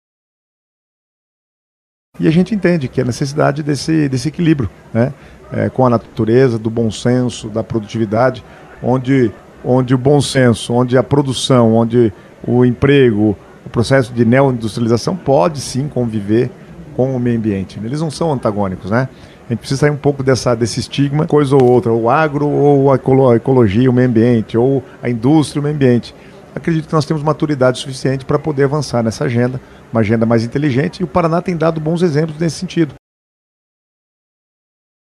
Na cerimônia de abertura, realizada pela manhã no Teatro Guaíra, o Paraná apresentou iniciativas voltadas à sustentabilidade. Um dos porta-vozes do estado neste sentido foi o secretário Guto Silva, que destacou a necessidade de uma visão que alie desenvolvimento econômico e preservação.